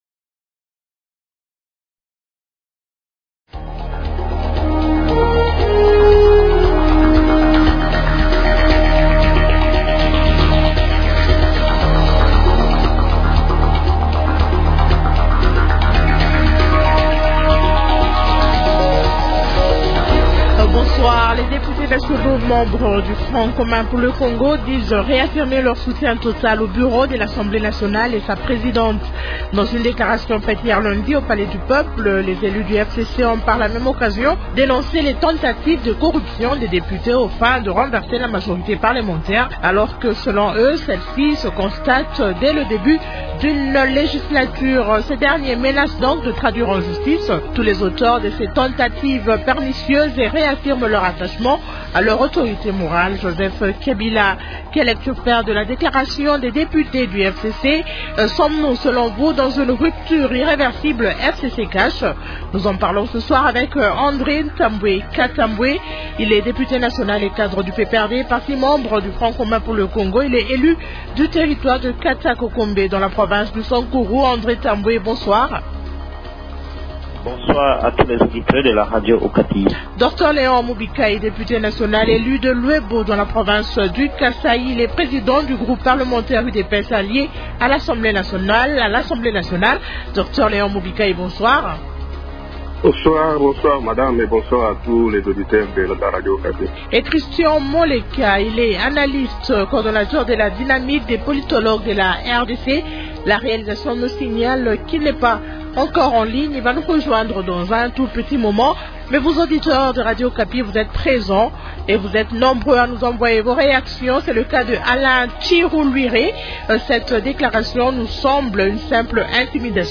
Invités : -André Tambwe Katambwe, Député national et cadre du PPRD, parti membre du Front commun pour le Congo (FCC).
-Dr Léon Mubikayi, Député national, élu de Luebo dans la province du Kasaï.